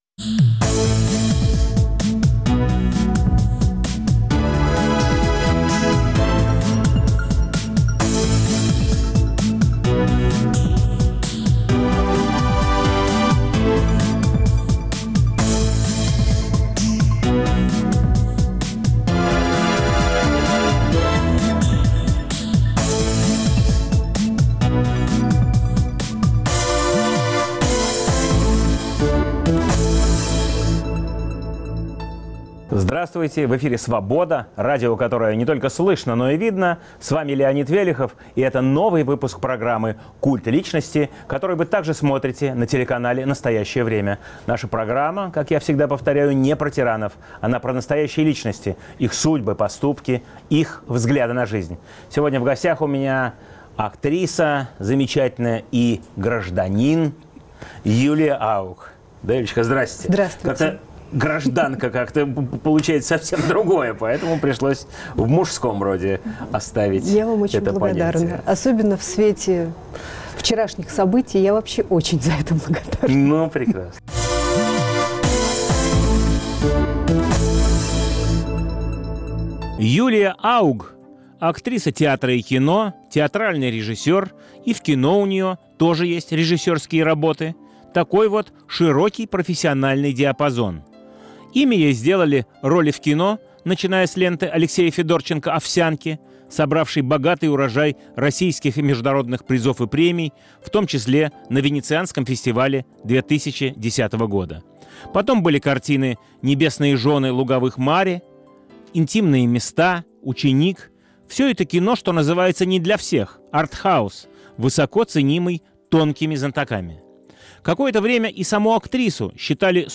В студии "Культа личности" актриса и режиссер Юлия Ауг.